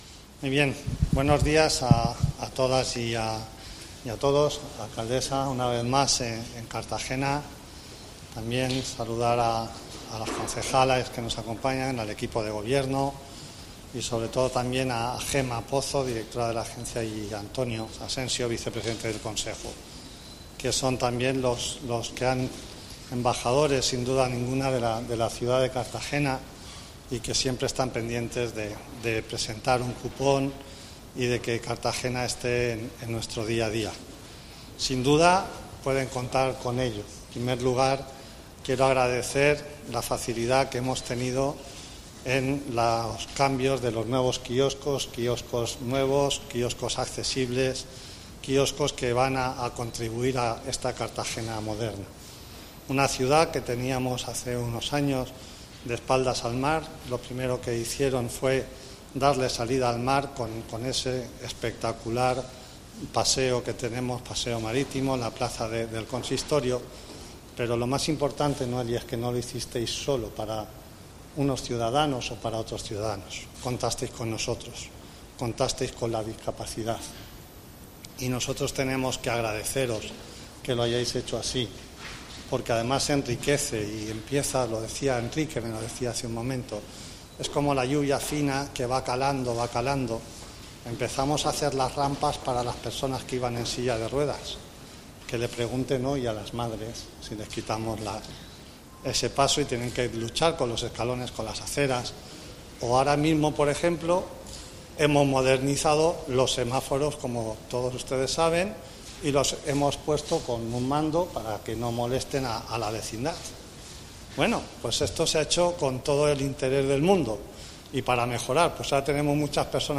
Delcaraciones